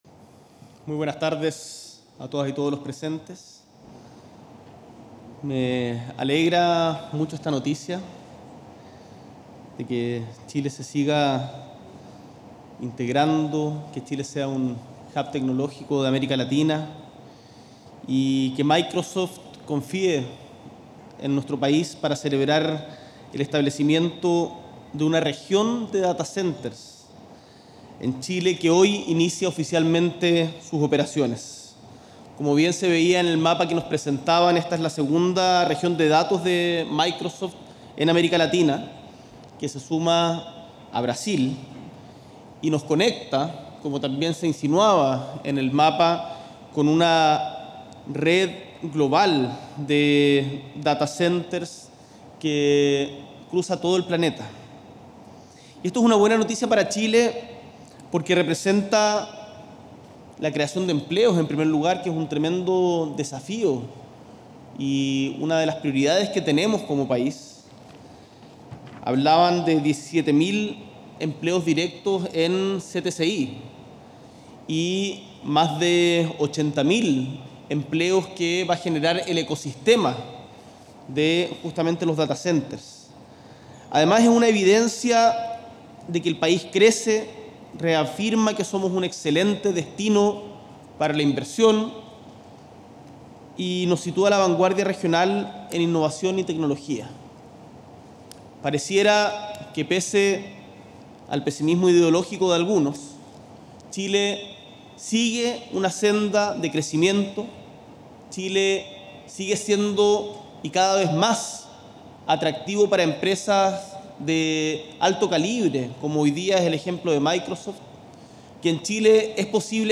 Presidente Gabriel Boric encabezó ceremonia que da inicio a las operaciones de la nueva región de datacenters de nube de Microsoft en Chile